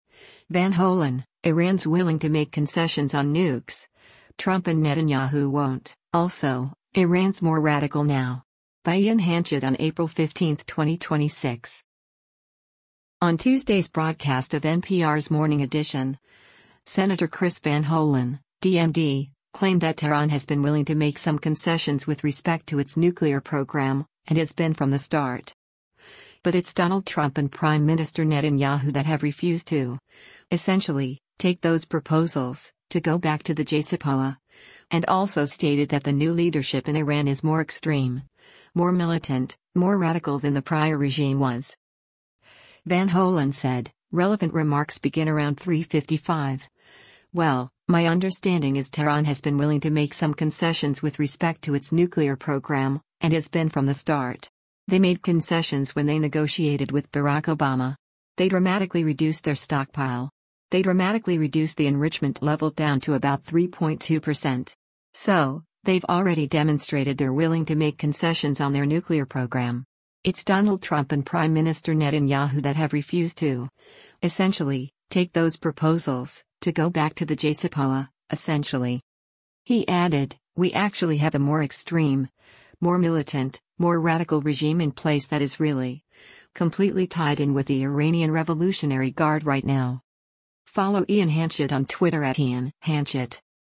On Tuesday’s broadcast of NPR’s “Morning Edition,” Sen. Chris Van Hollen (D-MD) claimed that “Tehran has been willing to make some concessions with respect to its nuclear program, and has been from the start.”